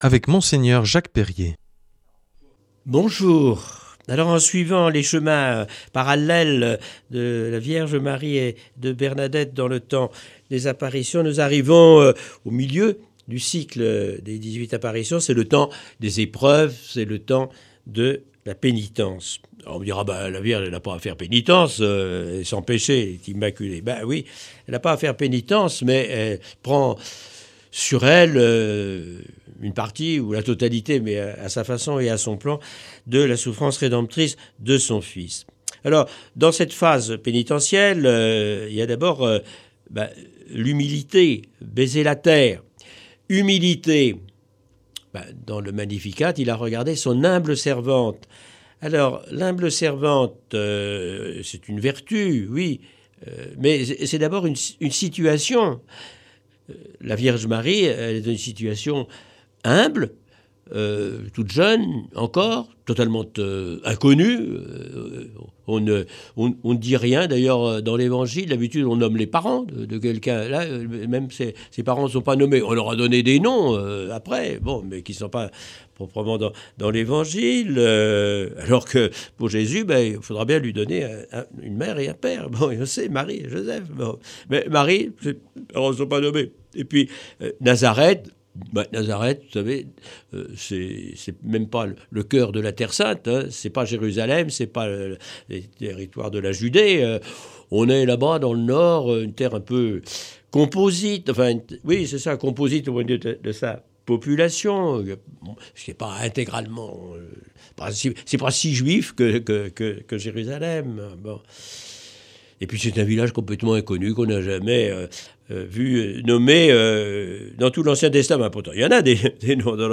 L’enseignement marial de cette semaine nous est proposé par Mgr Jacques Perrier. Il nous amène à faire un parallèle entre l’itinéraire de Bernadette Soubirous à travers les apparitions à Lourdes et la vie de la Vierge Marie.